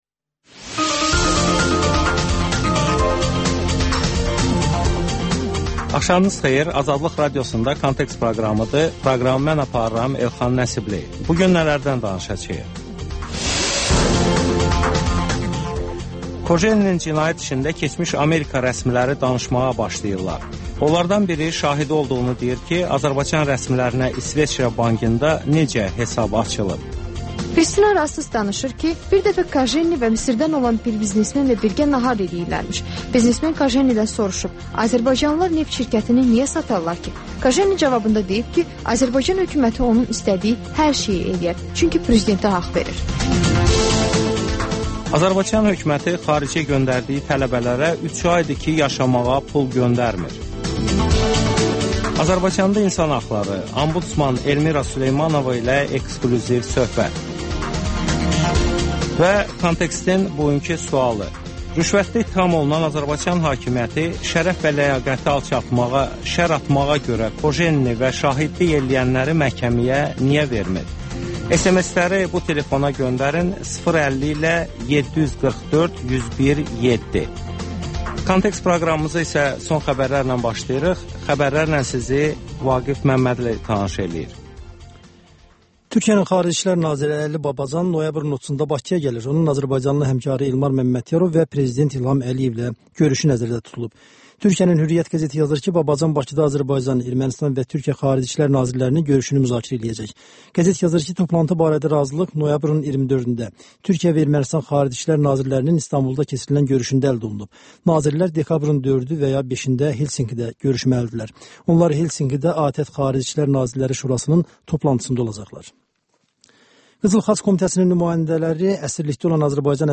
Xəbərlər, müsahibələr, hadisələrin müzakirəsi, təhlillər, daha sonra 14-24: Gənclər üçün xüsusi veriliş